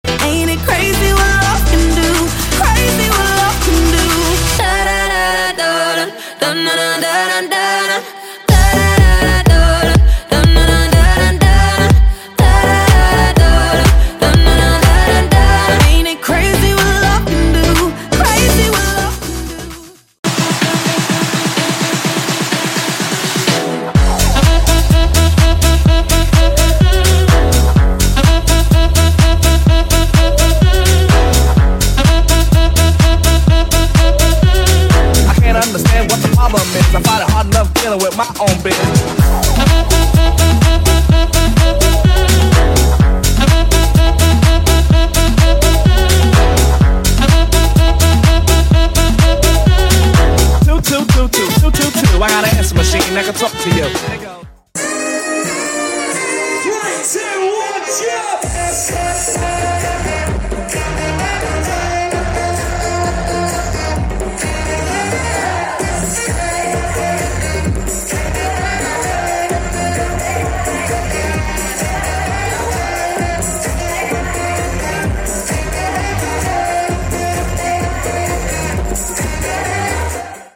Ibizia-inspired, high-energy party saxophonist!